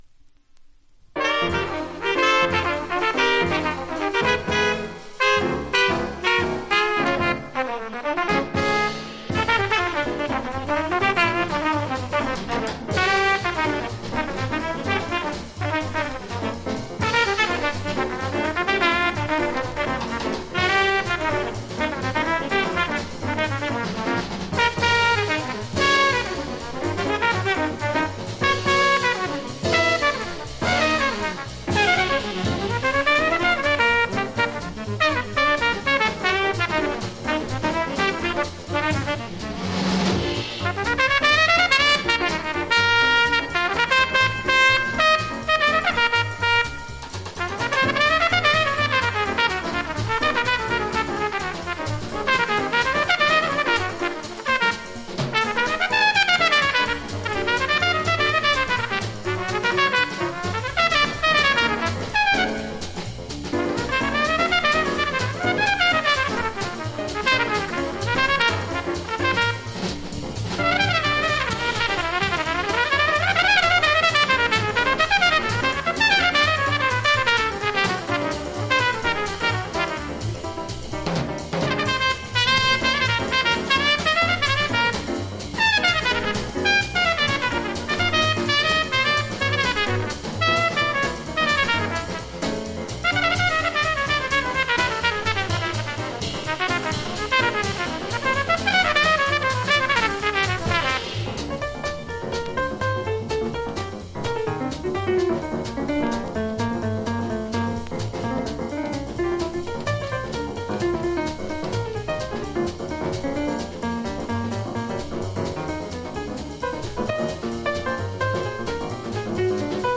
（MONO針で聴くとほとんどノイズなし）
Genre US JAZZ